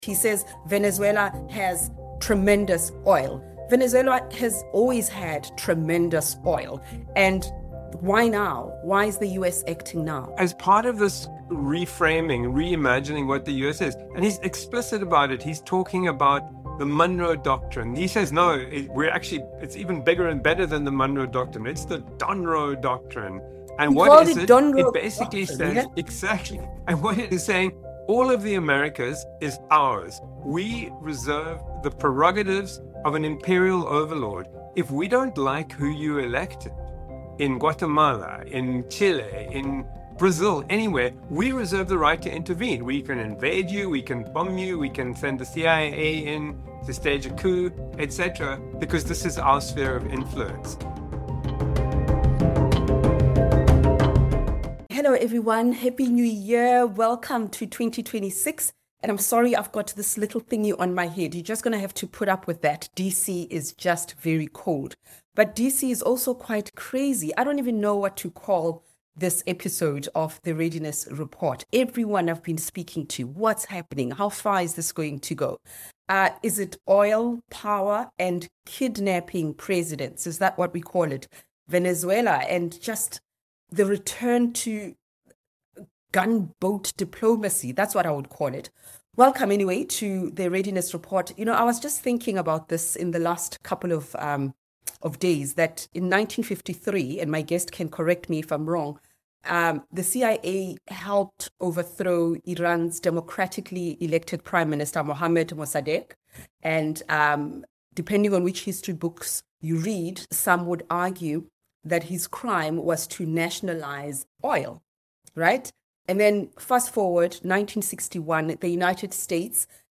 Hosted by: Redi Tlhabi Guest